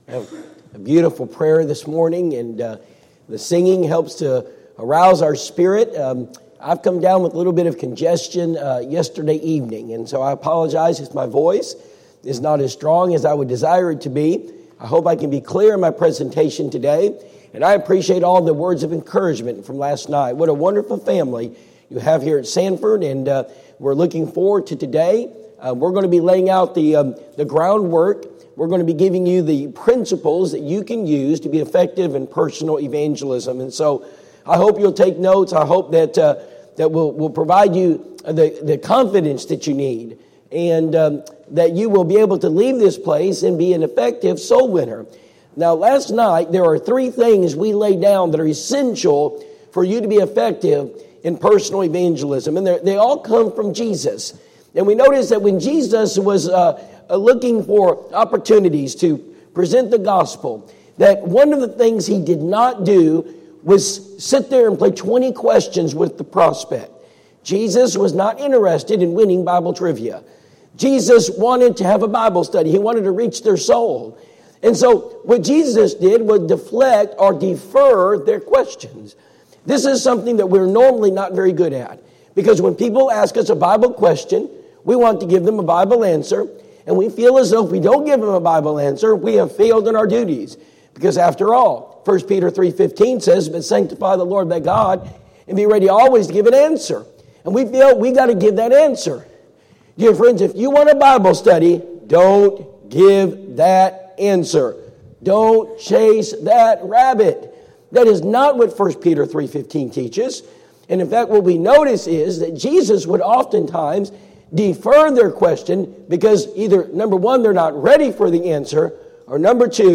Personal Evangelism Seminar Service Type: Personal Evangelism Seminar Preacher